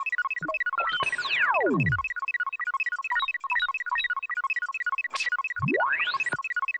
Added an audio track for the ACME Crimenet Computer's idle noise.
ACME Crimenet Computer Idle.wav